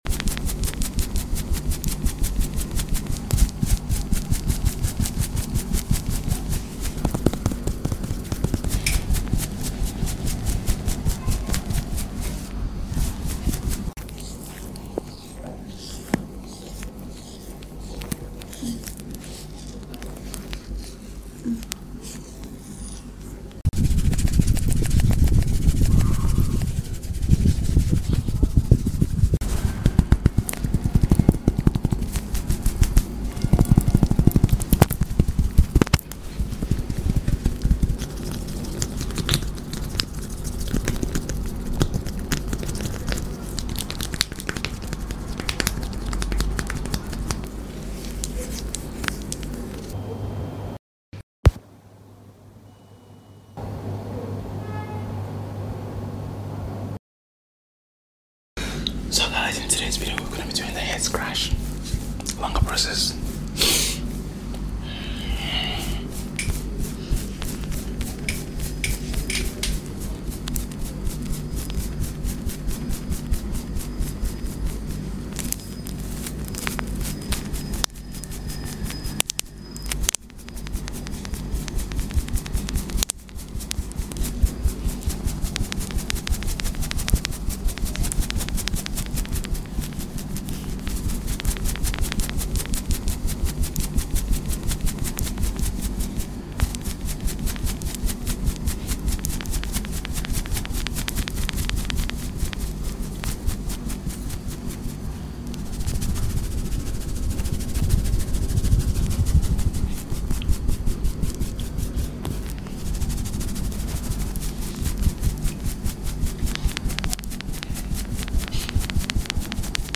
ASMR AGGRESSIVE HEAD SCALP SCRATCH sound effects free download
ASMR AGGRESSIVE HEAD SCALP SCRATCH MASSAGE | EAR CLEANING 4 RELAXATION SLEEP